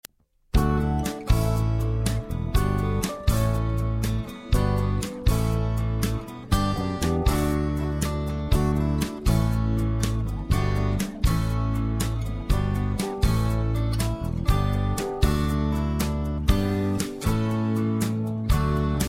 Listen to a sample of this instrumental song.